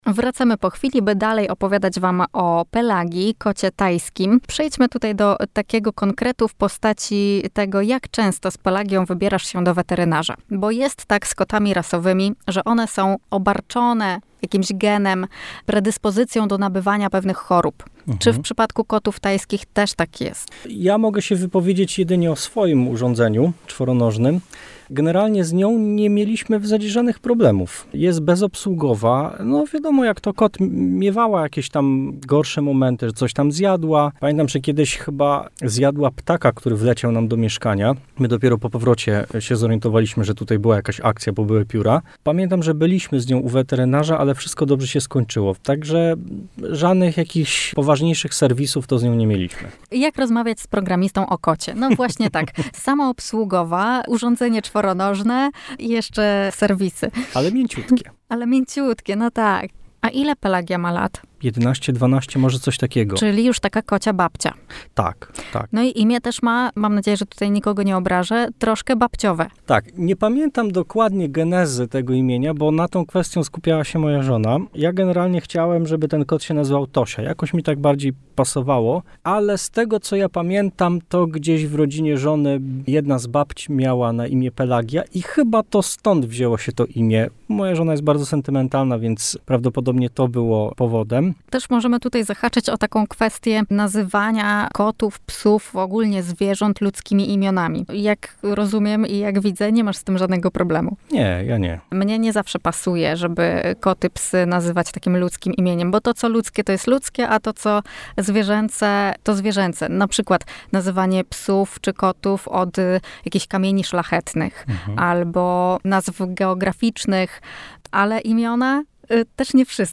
Rozmowa toczyła się nie tylko na temat tejże rasy i jej charakterystyki, ale również w odniesieniu do pracy z domu, jaką świadczą programiści.